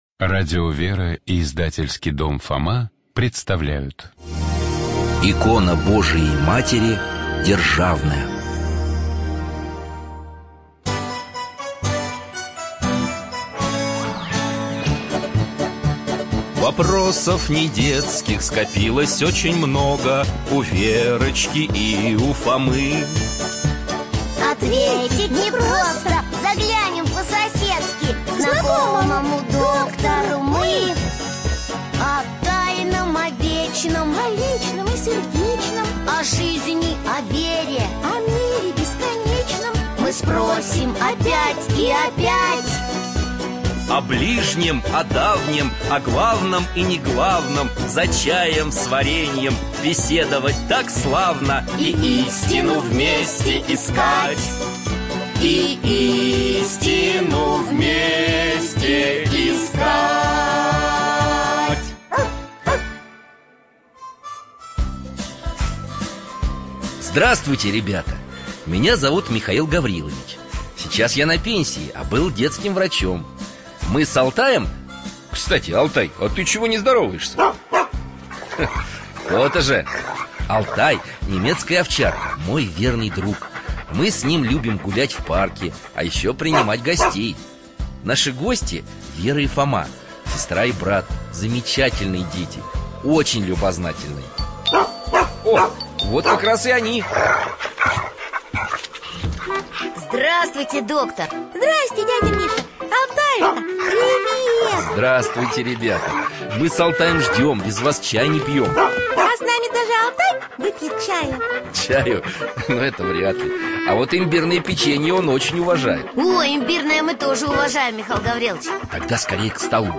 Студия звукозаписиРадио Вера